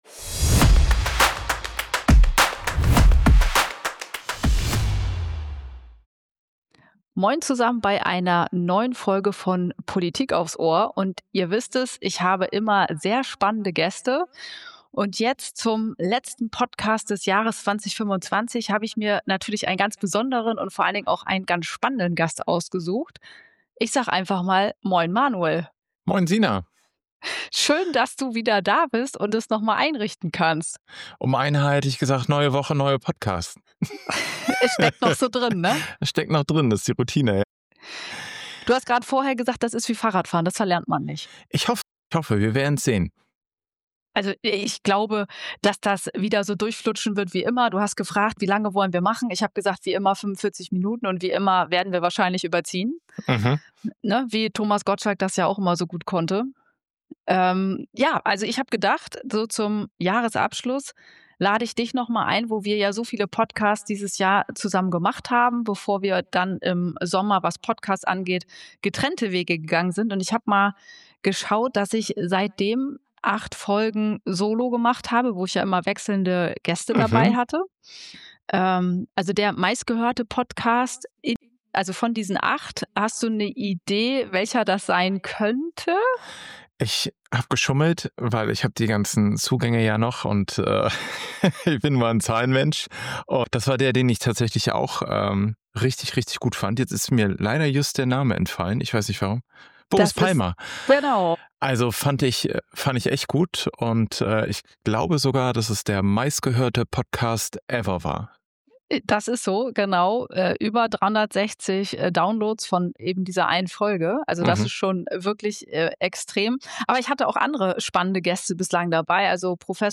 Jahresfinale bei „Politik aufs Ohr“ – ein Comeback, ein Rückblick, ein außergewöhnliches Gespräch.
Mal streitbar, mal nachdenklich, oft überraschend ehrlich.